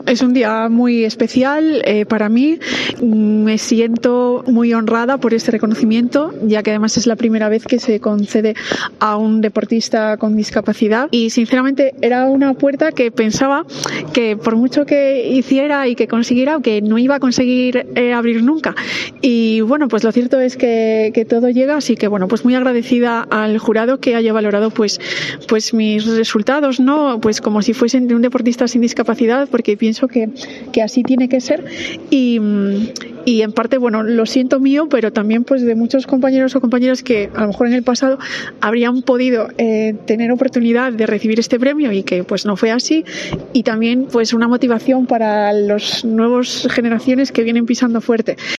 En declaraciones a los medios de comunicación congregados en la gala, Susana reconoció estar viviendo "un día muy especial" ya que el premio era una "puerta que no pensaba que podría abrir nunca".